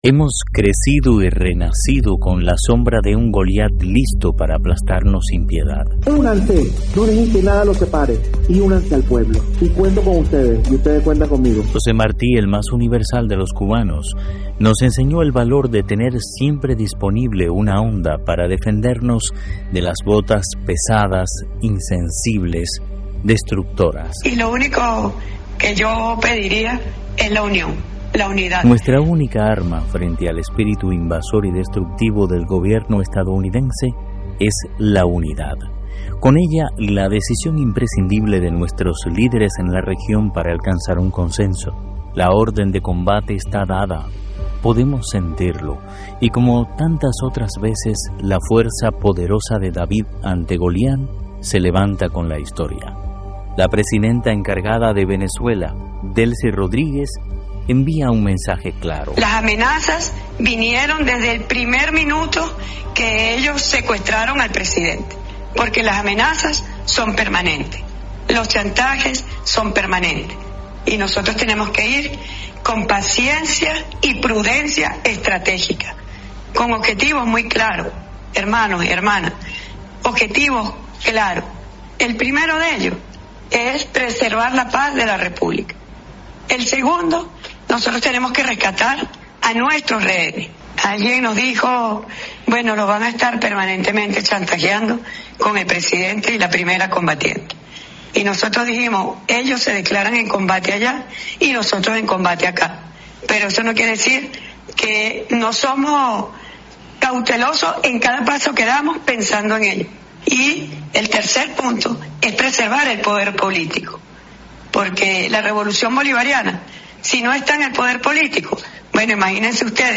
La presidenta encargada de Venezuela, Delcy Rodríguez envía un mensaje claro.